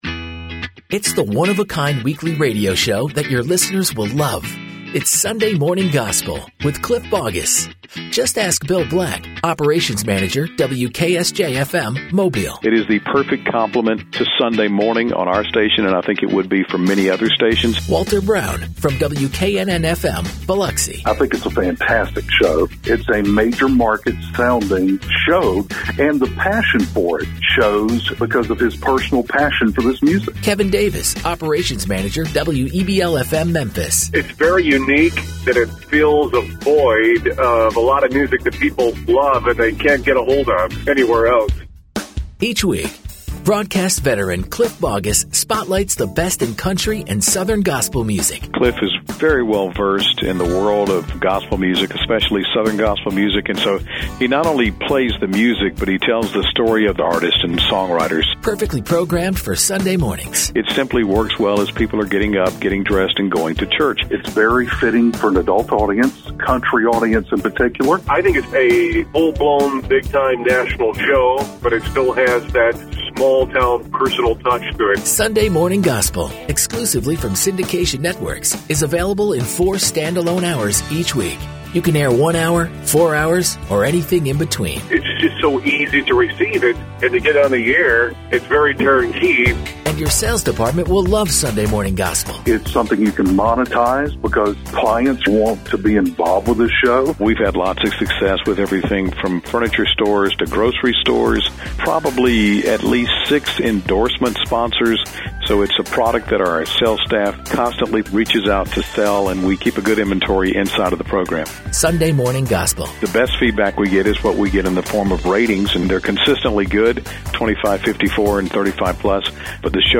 features the best in Country and Southern Gospel Music